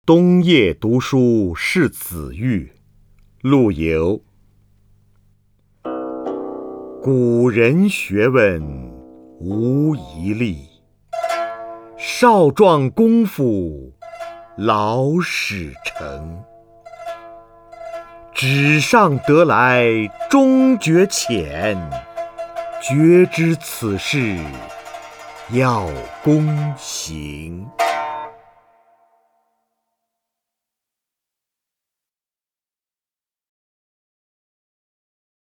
首页 视听 名家朗诵欣赏 瞿弦和
瞿弦和朗诵：《冬夜读书示子聿·其二》(（南宋）陆游)　/ （南宋）陆游